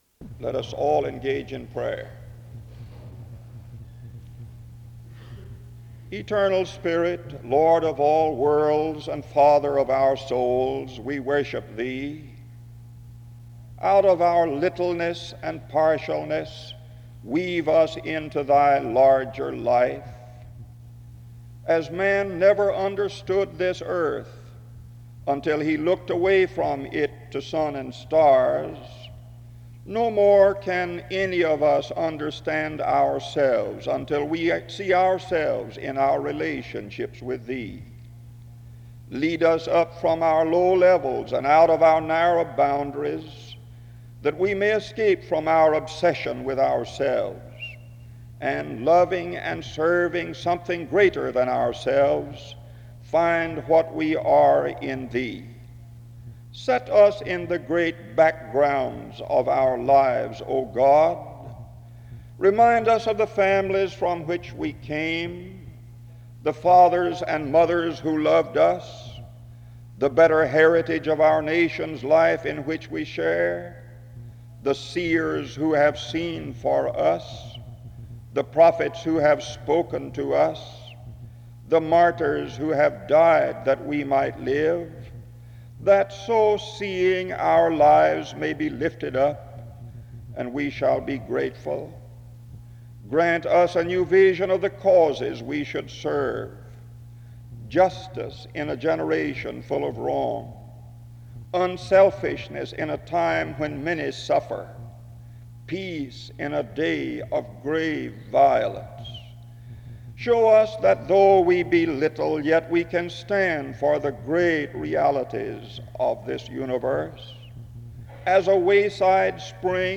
Download .mp3 Description After a prayer and a song (start-9:50)
SEBTS Chapel and Special Event Recordings SEBTS Chapel and Special Event Recordings